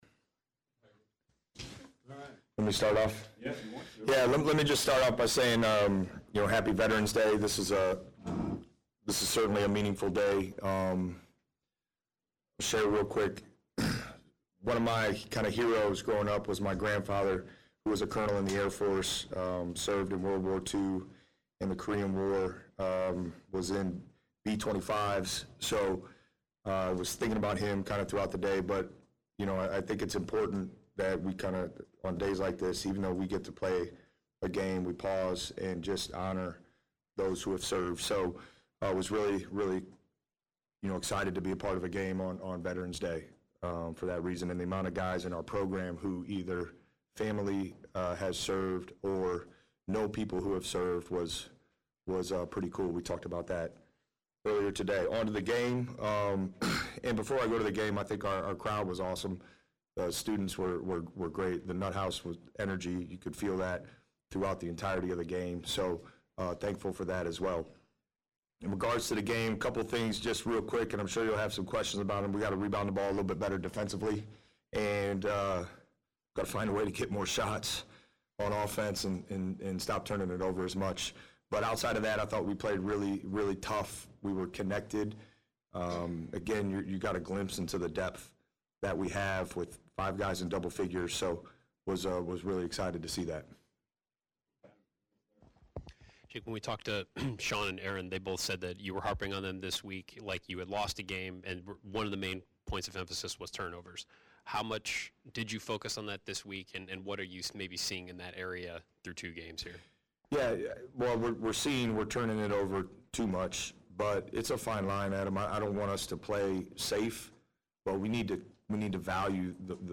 Postgame Presser